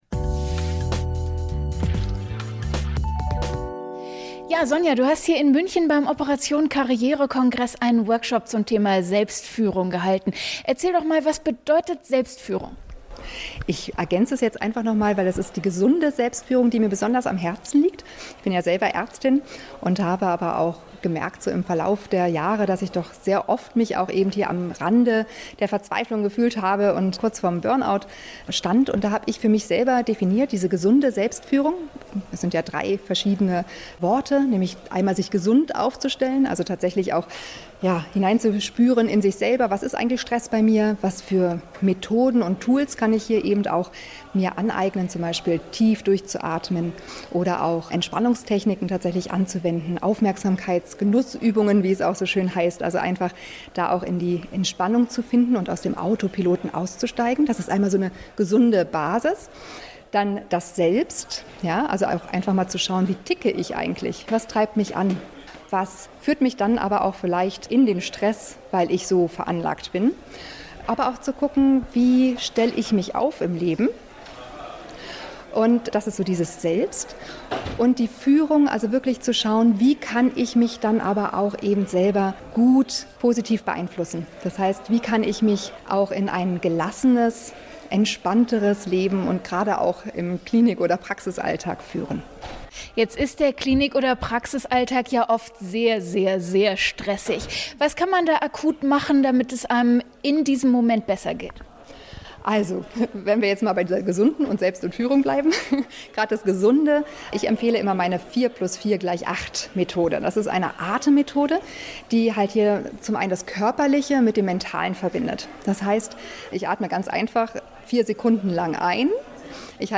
Wie kannst du den stressigen Klinikalltag überstehen und was hilft, wenn manchmal alles zu viel wird? Im Interview